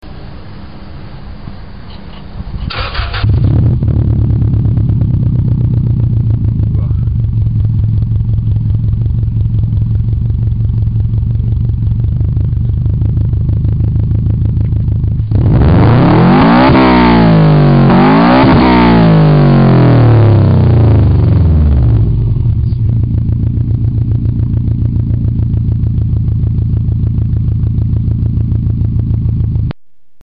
若干音質が劣化してますがご勘弁を（；−−）ﾉ
レベル１が静かな方で、逆にレベル５が爆音です。
登録No． パーツの種類 マフラー
音量 レベル４
・インナーサイレンサー無し。